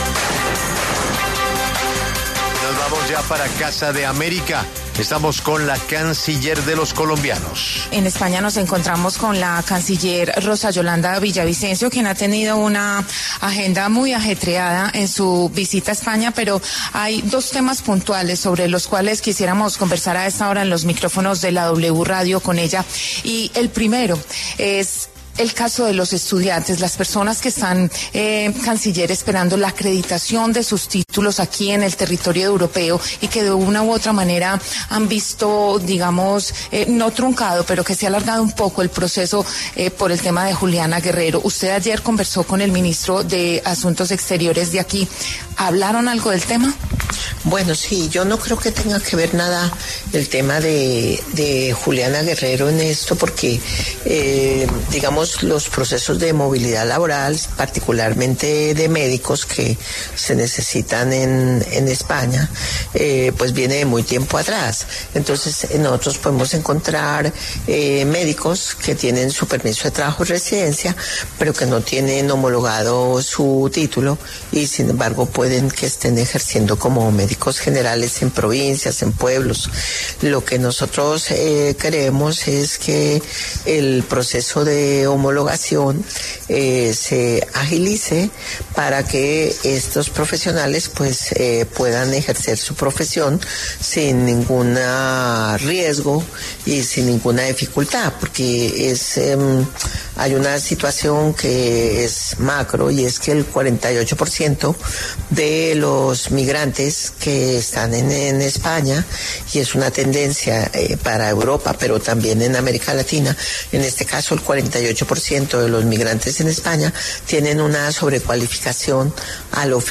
La canciller de Colombia, Rosa Villavicencio, conversó con La W sobre la homologación de títulos para universitarios colombianos en Europa y un posible acuerdo de paz con el Clan del Golfo.